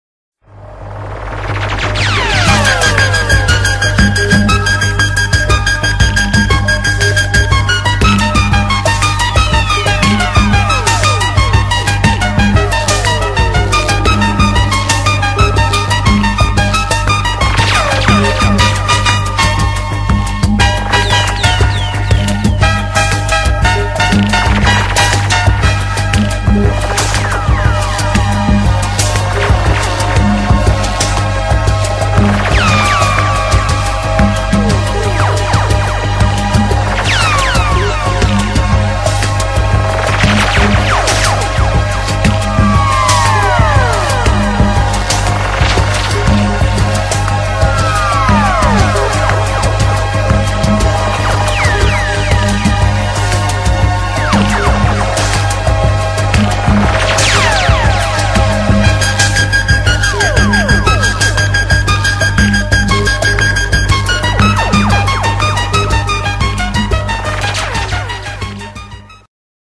música electrónica